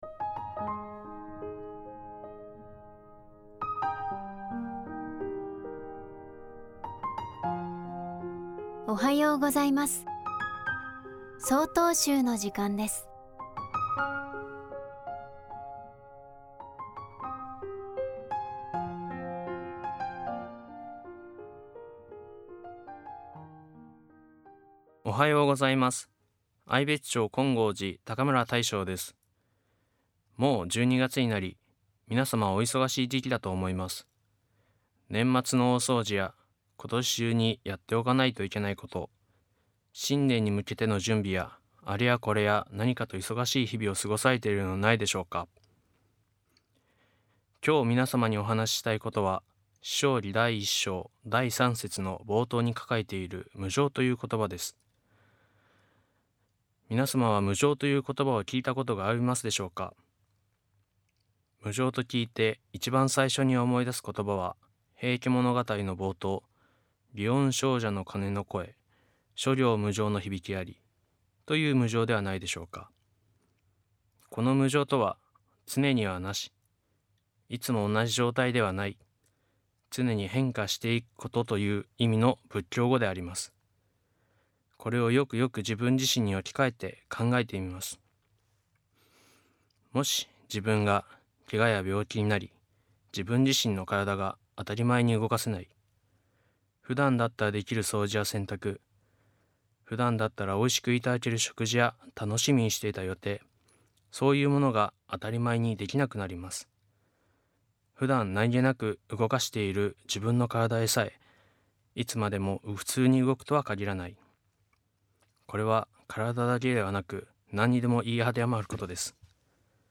法話